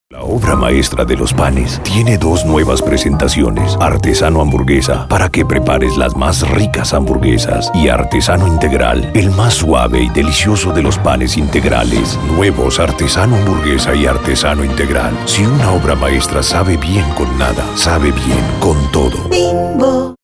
Male
Authoritative, Character, Conversational, Deep, Gravitas, Versatile, Warm